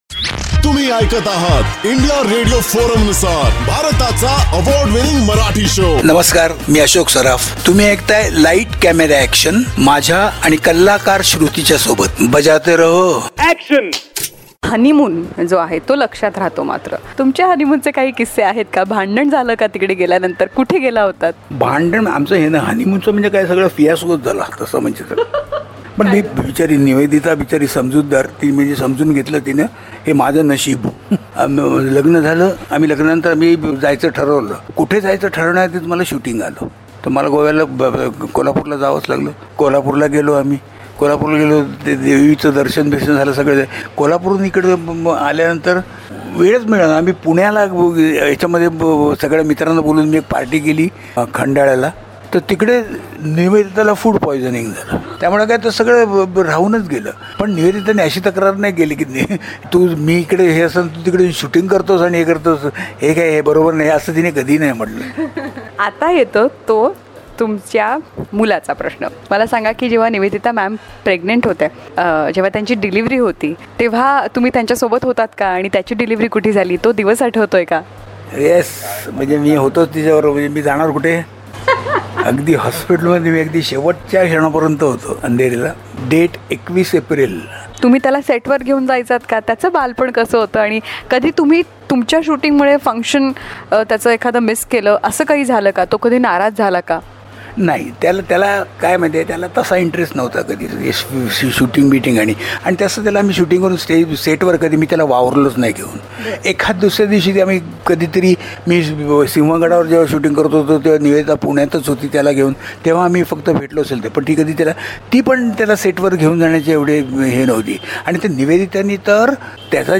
VETRAN ACTOR ASHOK SARAF SHARES FOND MEMORIES OF HIS MARRIAGE & HONEYMOON